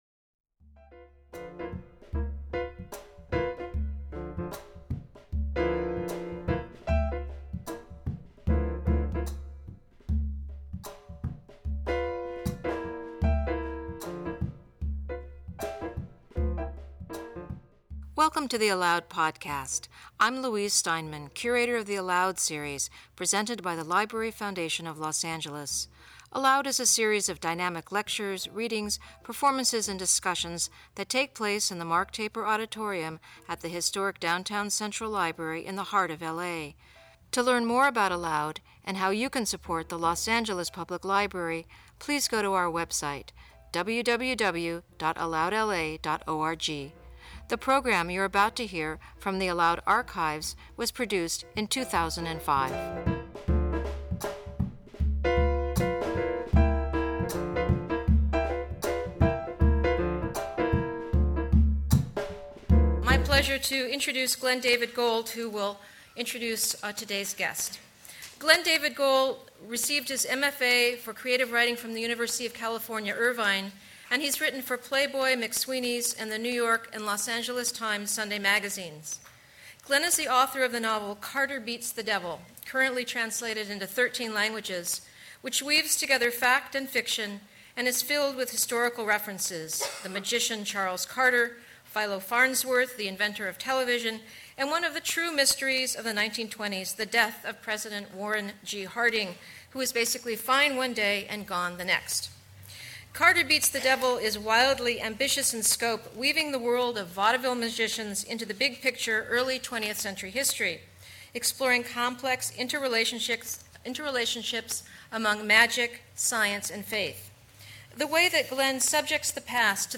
In conversation with Glen David Gold